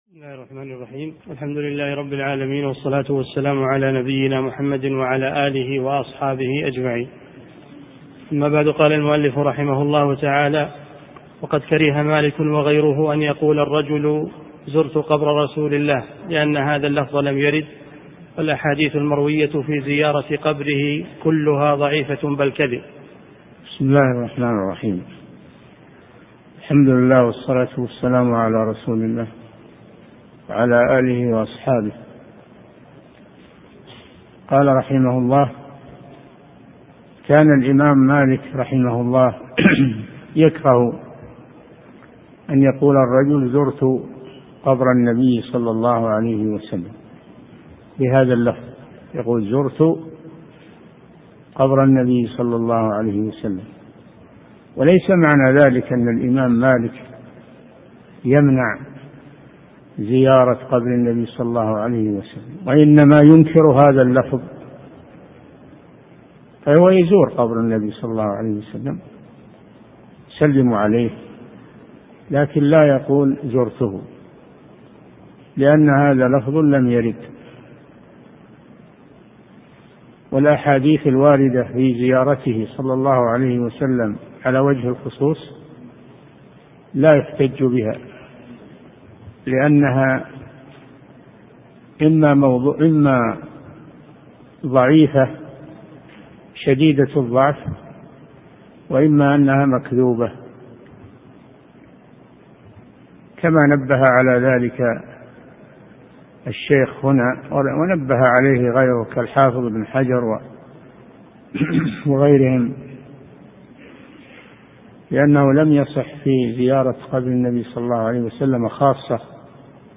أرشيف الإسلام - ~ أرشيف صوتي لدروس وخطب ومحاضرات الشيخ صالح بن فوزان الفوزان